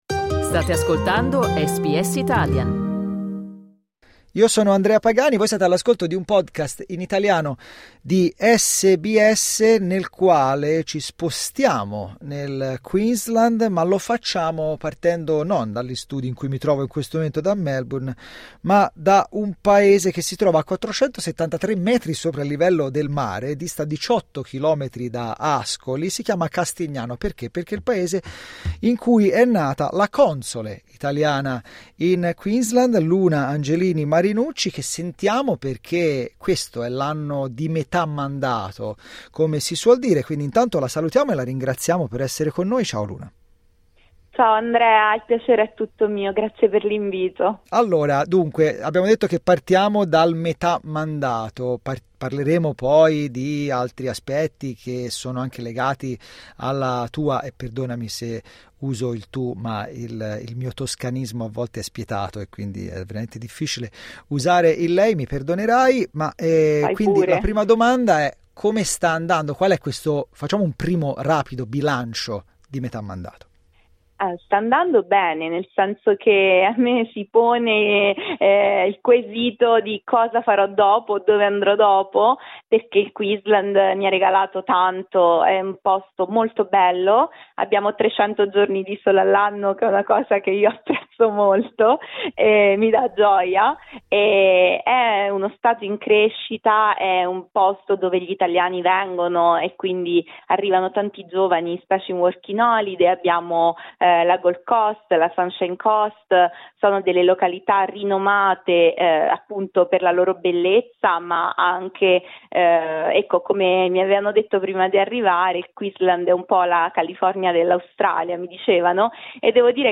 Clicca 'play' in alto per ascoltare l'intervista a Luna Angelini Marinucci Luna Angelini Marinucci e il Vespucci.